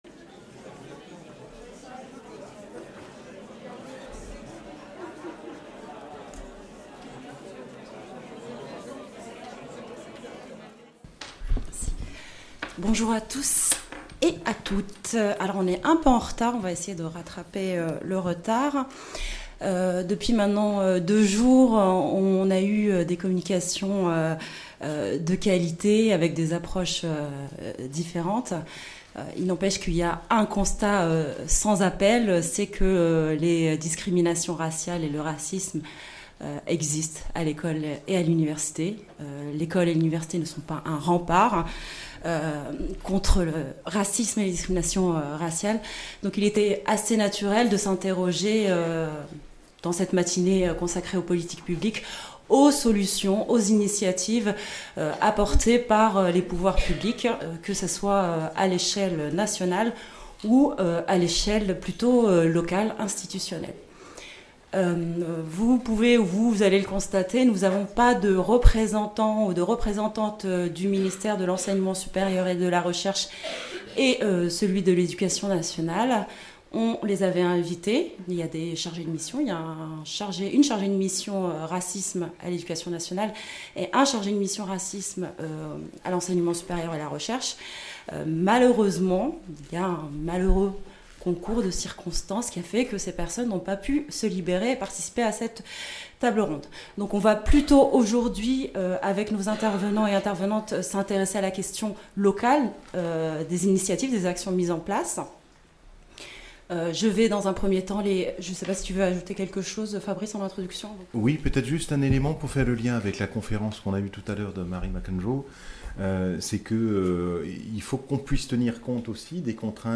Table-ronde : Quelles politiques et actions scolaires et universitaires face au racisme et aux discriminations ?